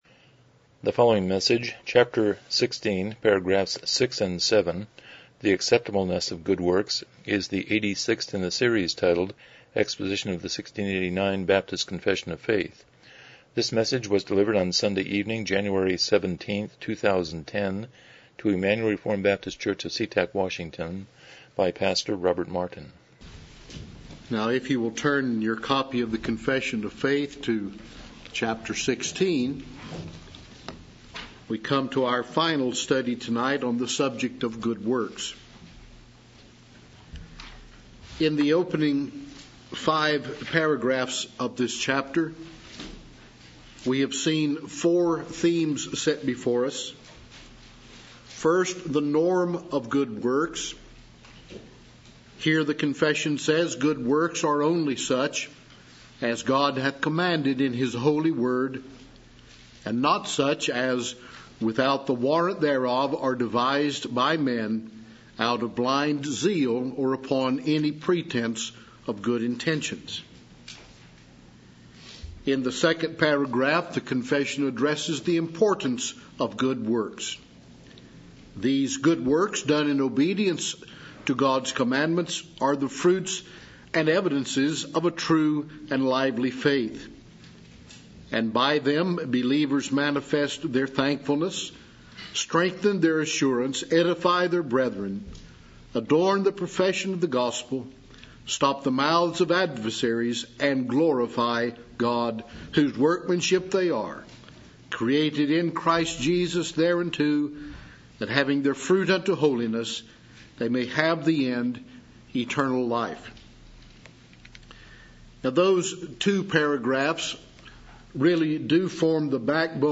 1689 Confession of Faith Service Type: Evening Worship « 101 Romans 8:17b